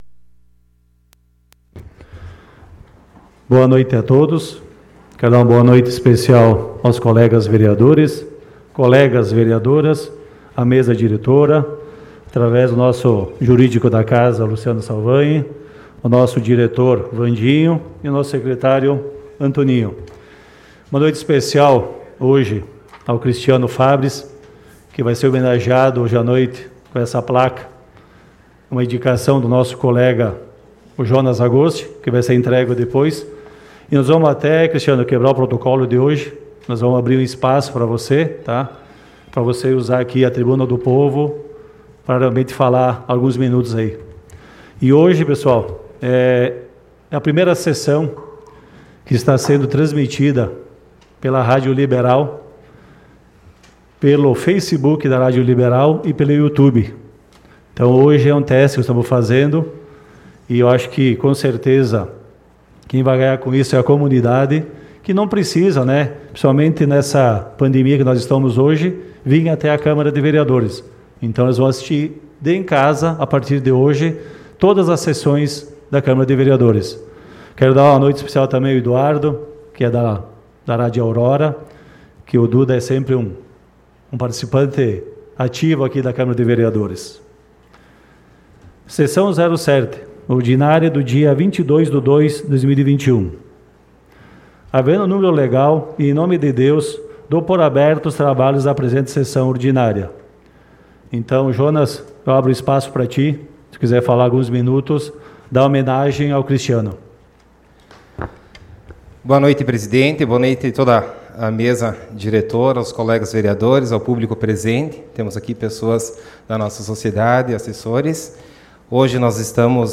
Sessão Ordinária do dia 22 de Fevereiro de 2021 - Sessão 07